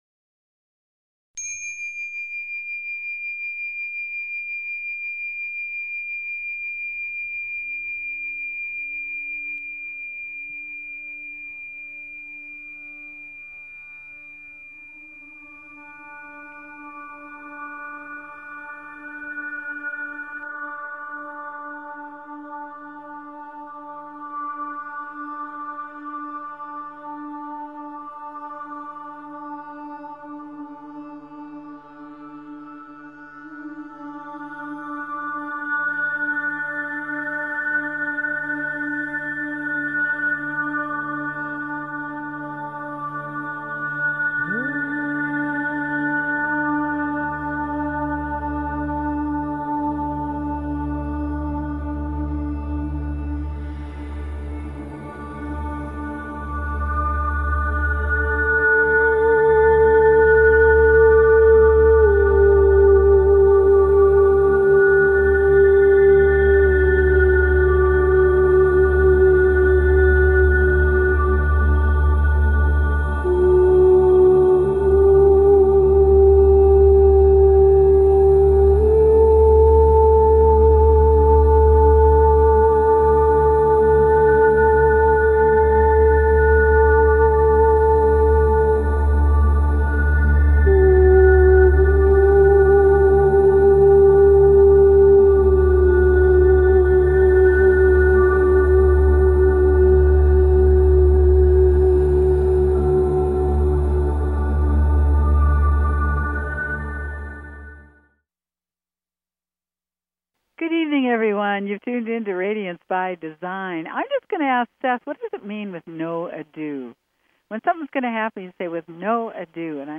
Talk Show Episode, Audio Podcast, Radiance_by_Design and Courtesy of BBS Radio on , show guests , about , categorized as
Show Headline Radiance_by_Design Show Sub Headline Courtesy of BBS Radio Radiance by Design - October 10, 2013 Radiance By Design Please consider subscribing to this talk show.